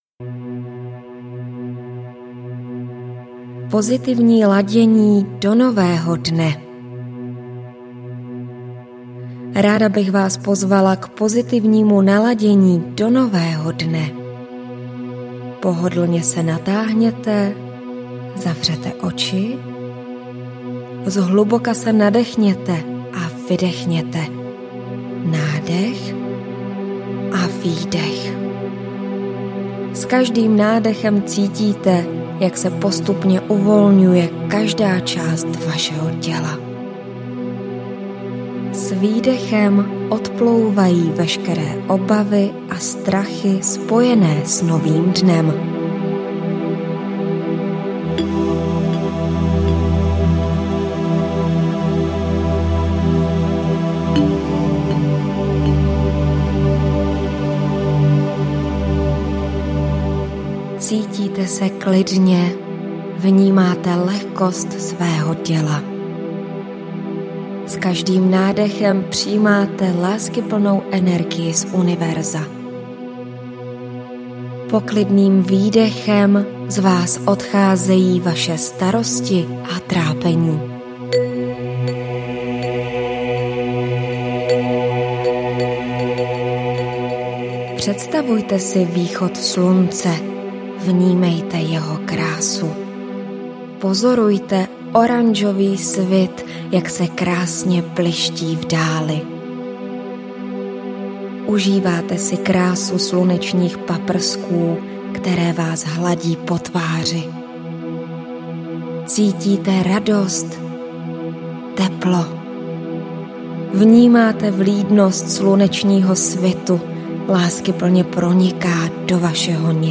Meditační CD Andělská naděje pro každý den přináší uklidňující relaxační program.
Ukázka z knihy
Při poslechu zapomenete na trápení i strarosti a necháte se hýčkat láskyplnou hudbou, která přinese blahodárnou regeneraci.